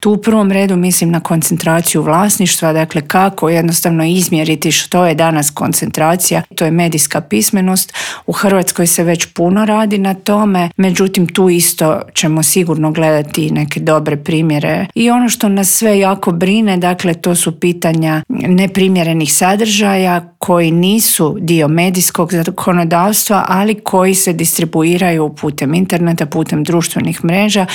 Jedna od uzvanica je ministrica kulture Nina Obuljen Koržinek s kojom smo o forumu, ali i Zakonu o elektroničkim medijima i primjerima dobre prakse medijske regulative u drugim eurpskim zemljama razgovarali u Intervjuu tjedna Media servisa.